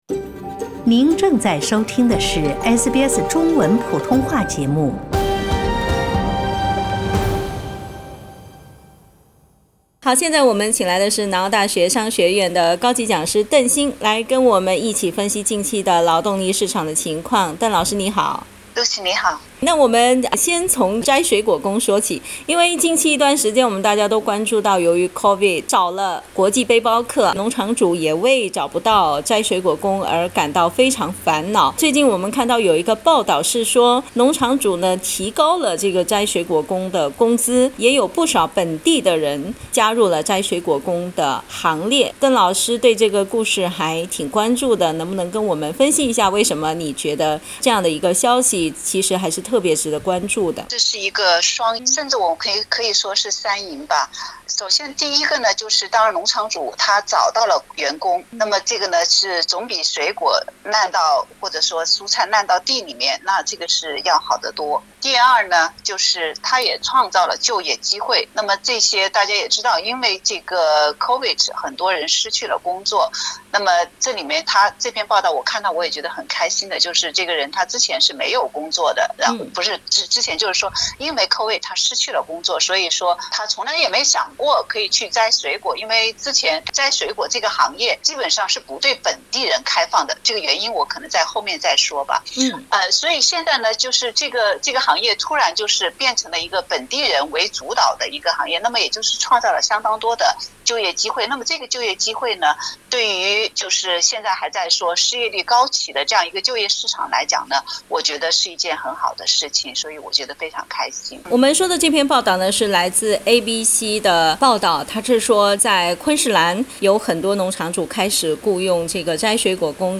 （请听采访） 澳大利亚人必须与他人保持至少 1.5 米的社交距离，请查看您所在州或领地的最新社交限制措施。